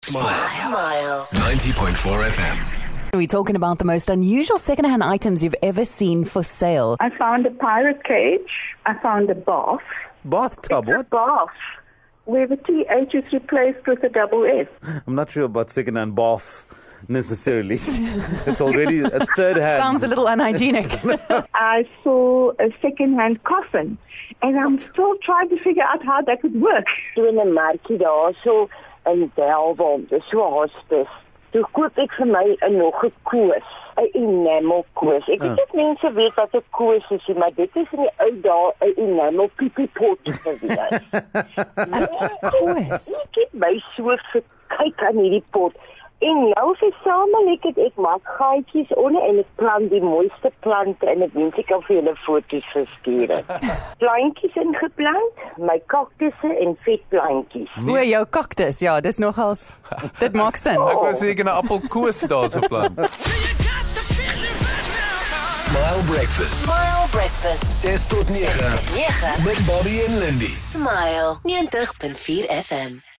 In a conversation about 2nd hand items for sale Smile Breakfast listeners told us about some of the things they found.